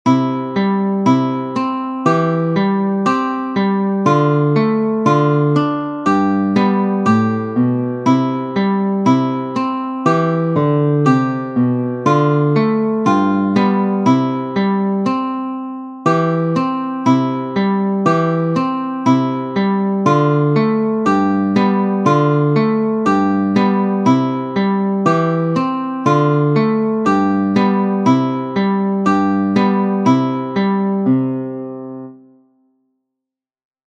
The mp3, like the Youtube is in 80 or 100bpm.
Click here to play along with both the student and teacher
ex2-with_accompaniment.mp3